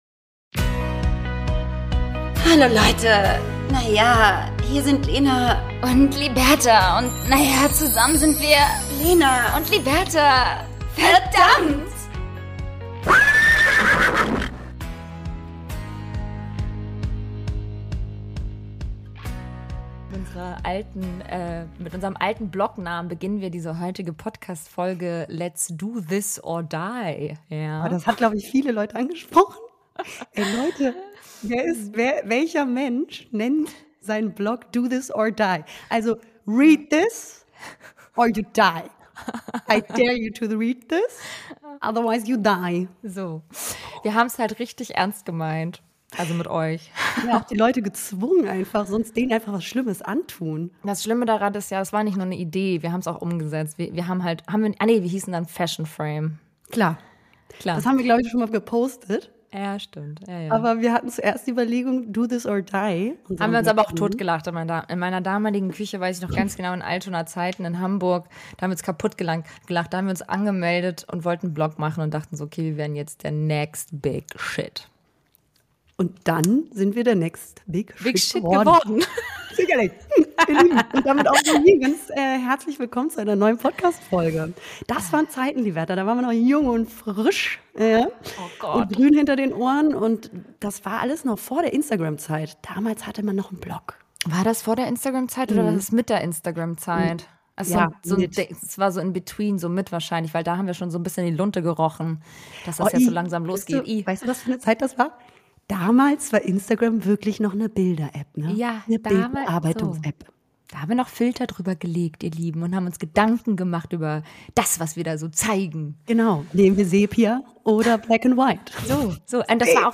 In dieser Folge wird wieder viel gelacht.